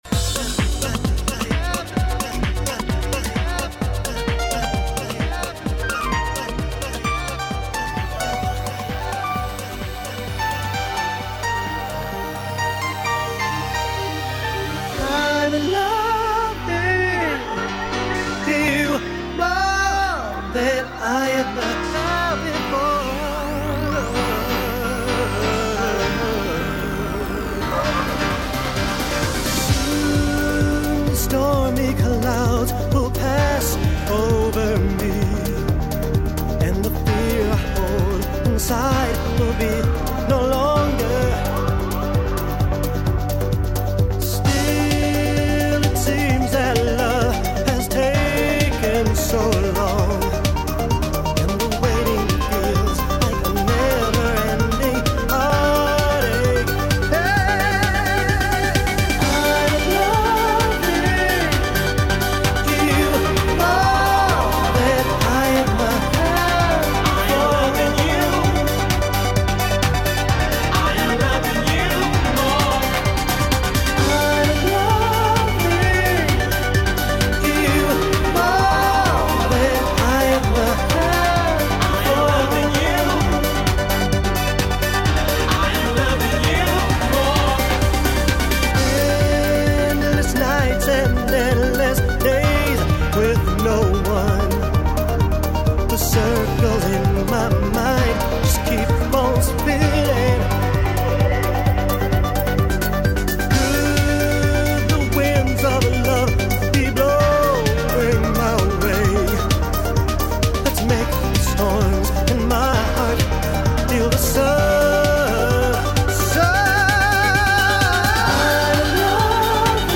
Транс музыка